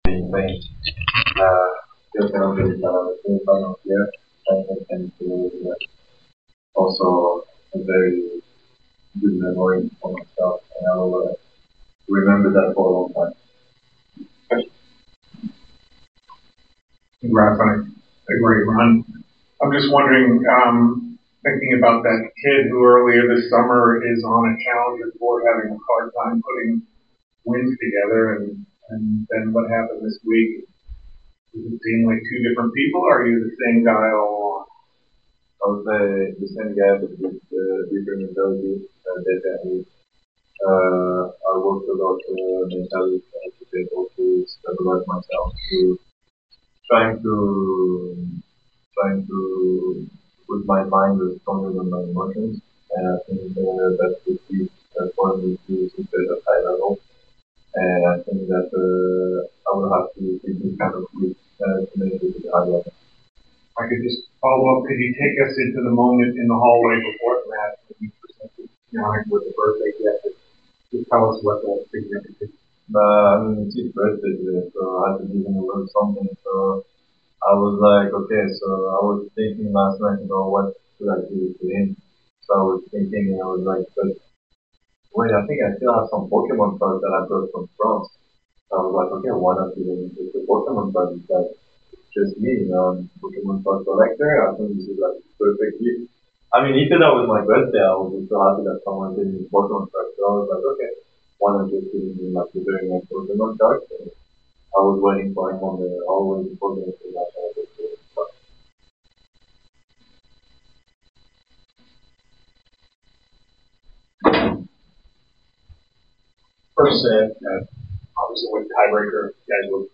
Terence Atmane post-match interview after losing to Jannik Sinner 6-7, 2-6 in the Semifinals of the Cincinnati Open.